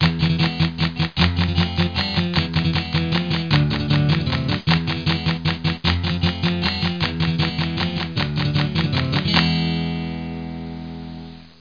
00850_Sound_Flamenco.mp3